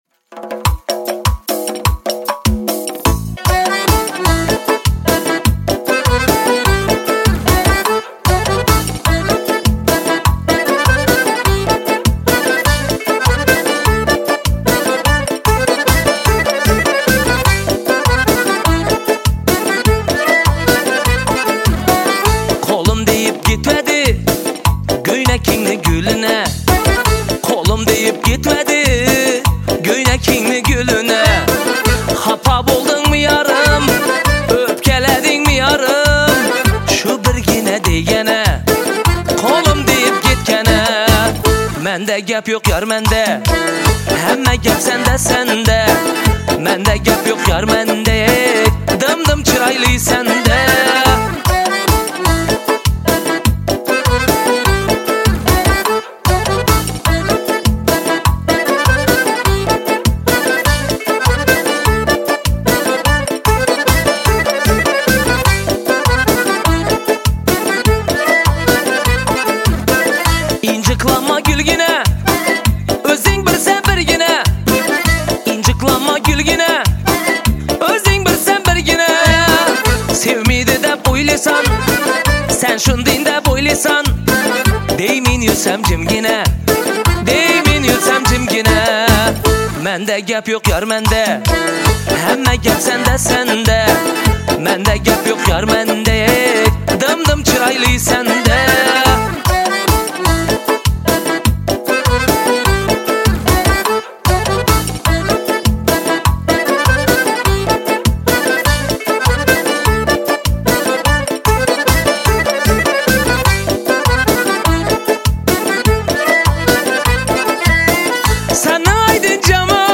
Жанр: Узбекская музыка